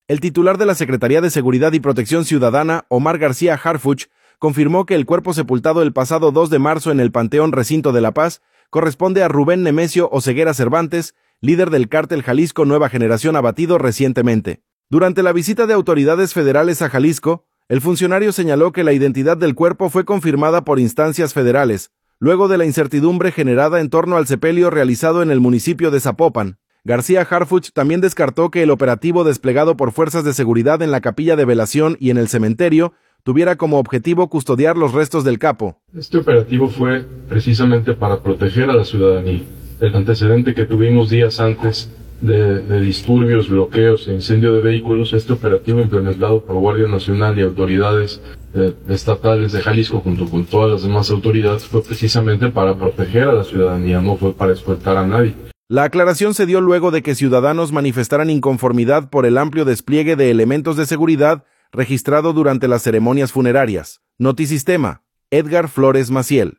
Durante la visita de autoridades federales a Jalisco, el funcionario señaló que la identidad del cuerpo fue confirmada por instancias federales, luego de la incertidumbre generada en torno al sepelio realizado en el municipio de Zapopan. García Harfuch también descartó que el operativo desplegado por fuerzas de seguridad en la capilla de velación y en el cementerio tuviera como objetivo custodiar los restos del capo.